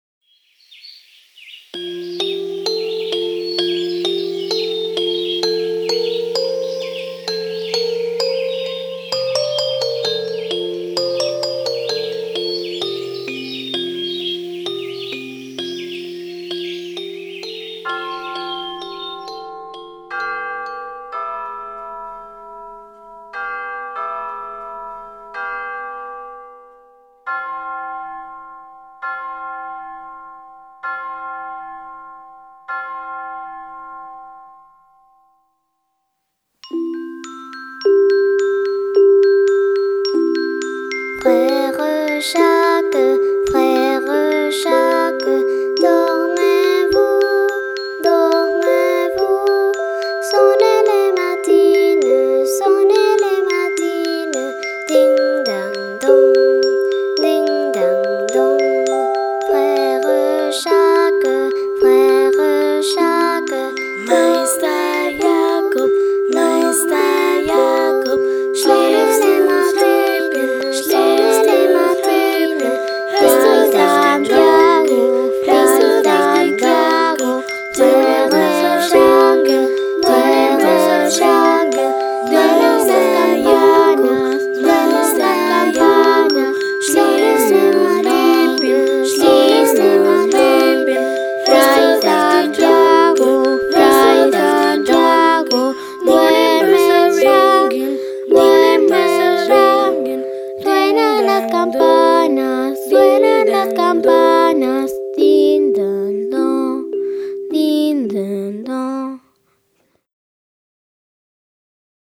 es cantada en canon